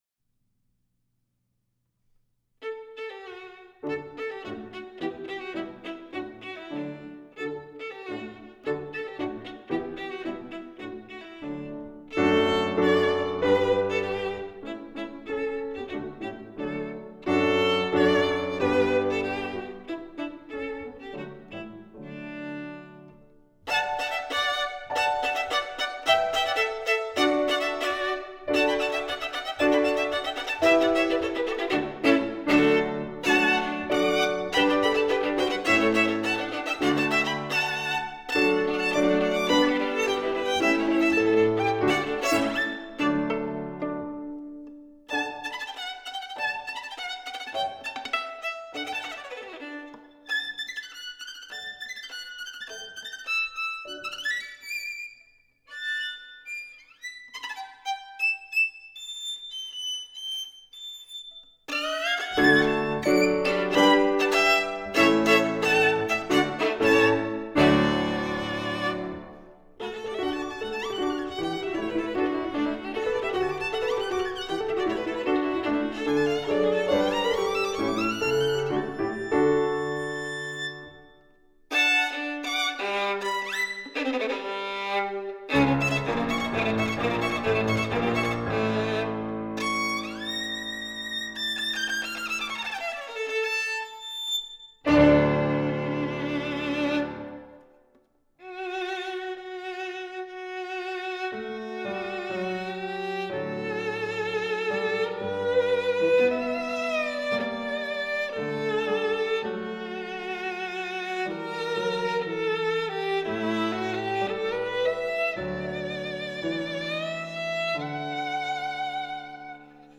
This composition is based upon a melody from an Israeli children’s song